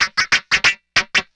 SCRAPEAGE 2.wav